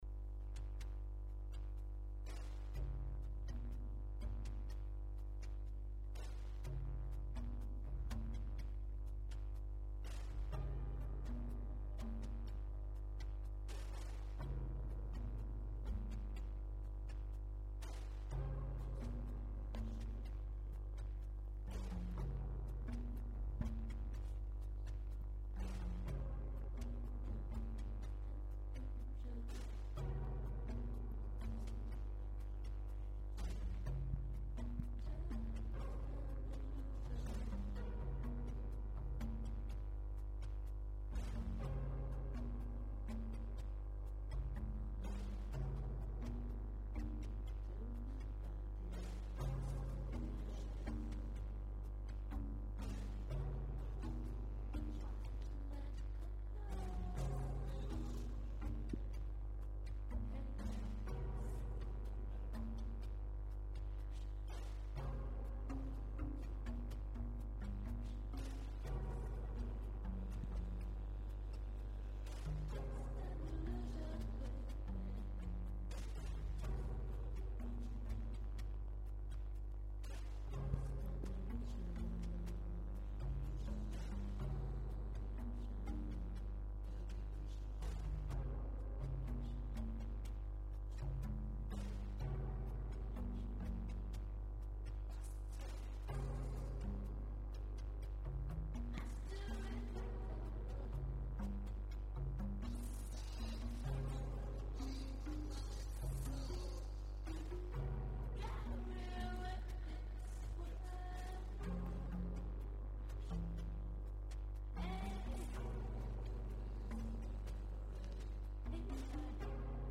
un autre sous forme d’impro mélangeant divers essais